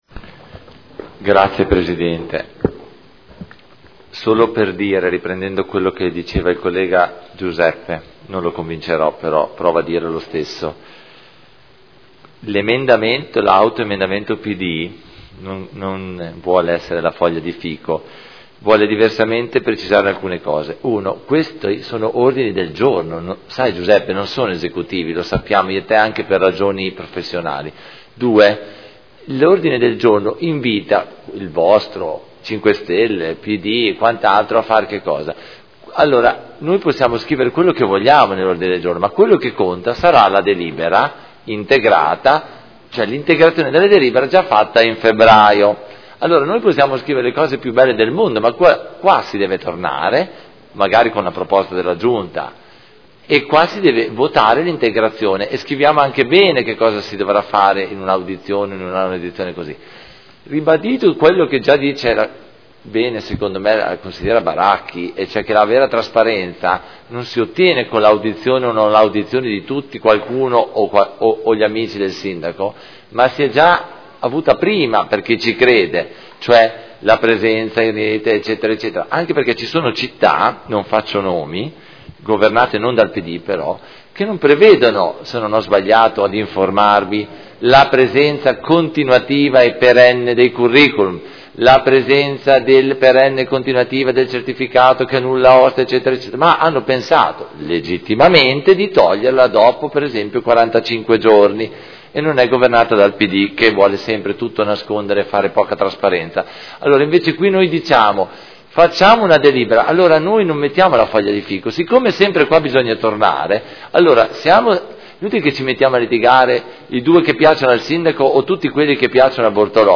Seduta del 09/12/2015 Dibattito. Ordini del giorno.